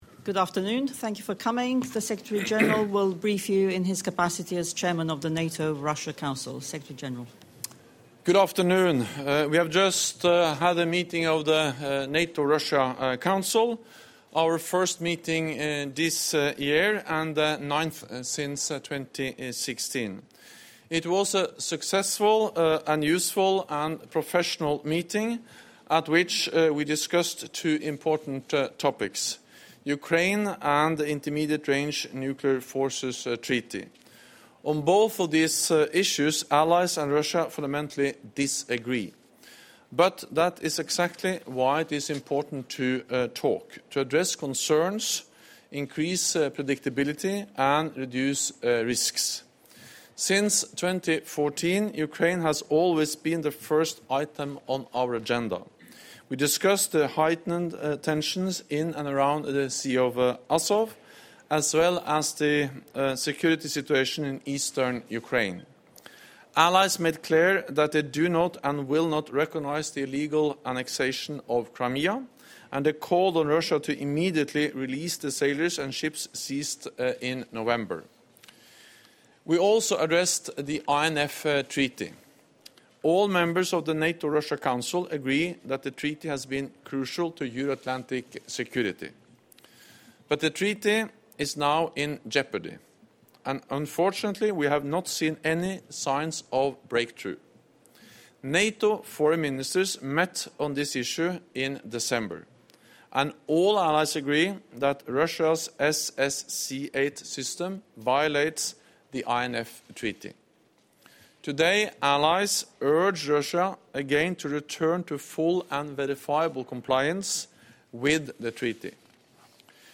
Press conference
by NATO Secretary General Jens Stoltenberg following the meeting of the NATO-Russia Council